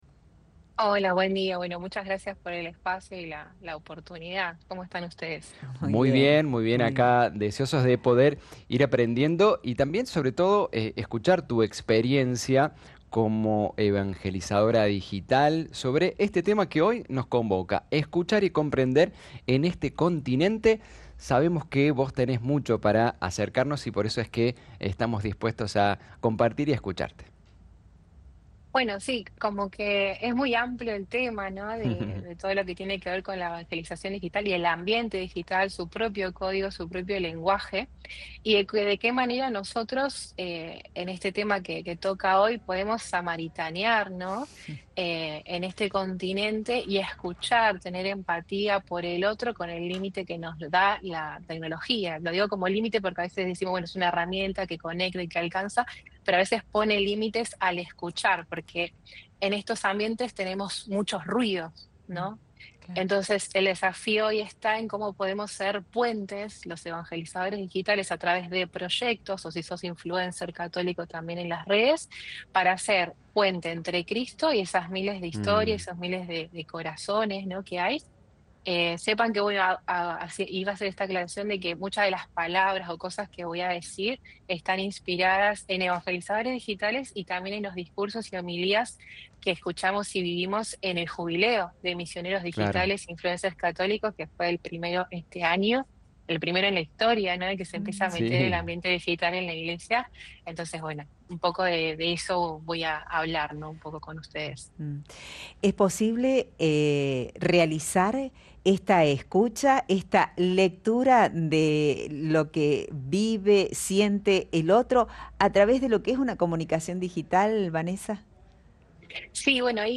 Es un sitio de formación y capacitación validado por la Universidad Nacional de San Luis y la Universidad della Santa Crocce, Roma, Italia. ¡Dale click al audio para escuchar la entrevista!